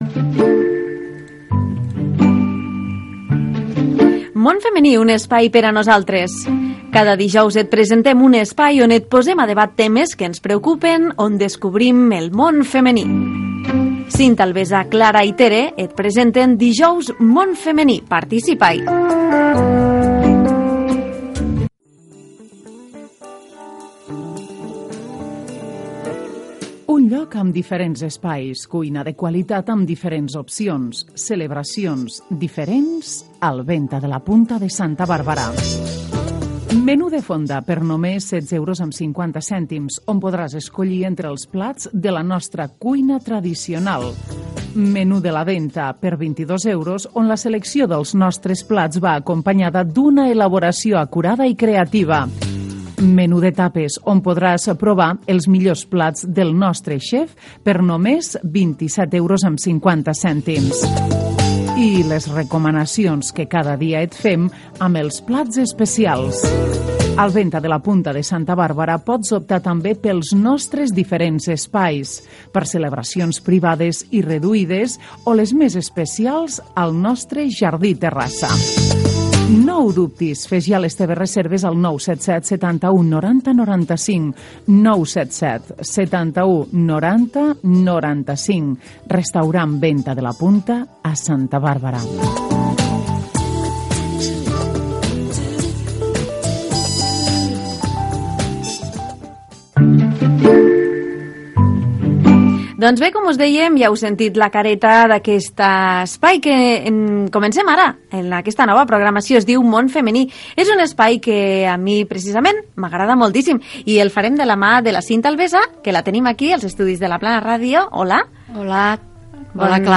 ef864fe168a12a751cf6a924e5c320e4649ac485.mp3 Títol La Plana Ràdio Emissora La Plana Ràdio Titularitat Pública municipal Nom programa Món femení Descripció Primera edició del programa. Careta, publicitat, presentació de l'equip i objectius, els canvis de la dona a la societat, trucada telefònica, tema musical
Entreteniment